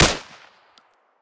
m16a2_fire.3.ogg